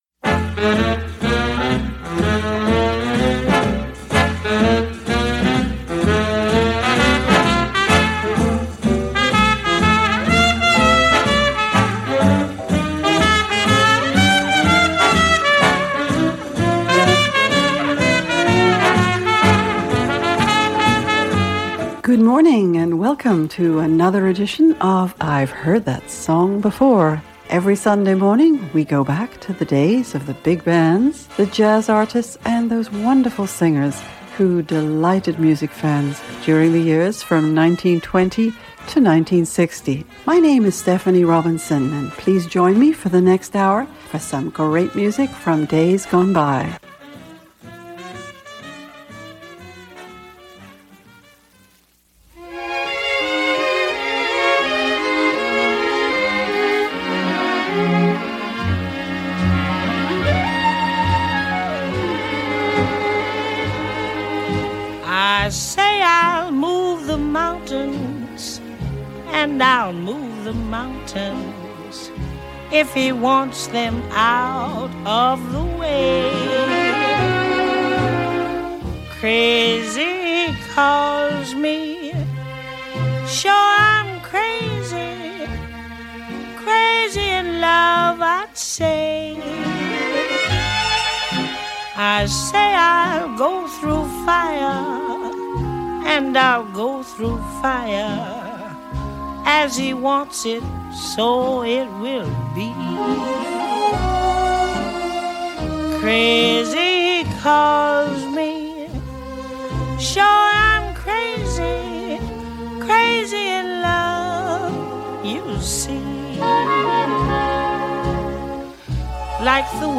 Enjoy these lovely voices.